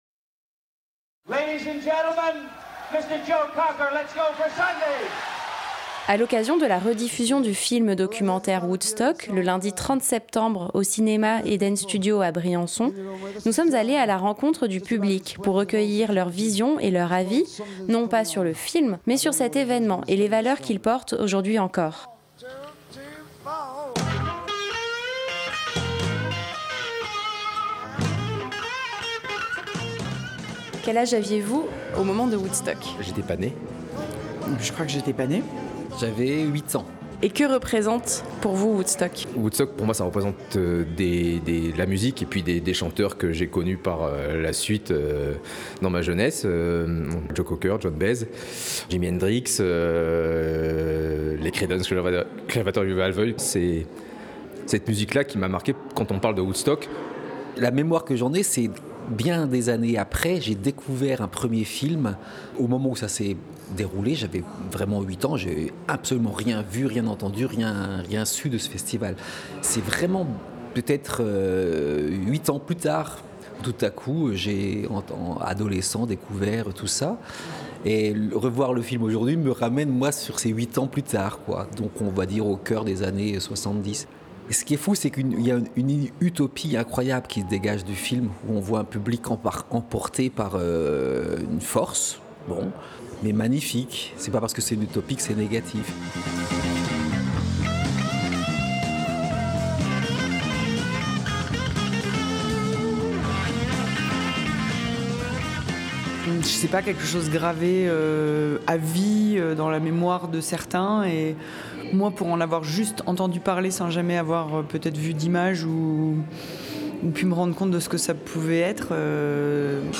Micro-trottoir - Que reste t-il aujourd'hui de Woodstock ?
Dans le cadre du festival Play it again , l 'Eden Studio a projeté le film documentaire Woodstock le lundi 30 septembre 2024. Nous sommes allés rencontrer les spectateur.rice.s pendant l'entracte pour leur demander ce que représente ce festival pour eux, et ce qu'il reste aujourd'hui des valeurs qu'il a portées.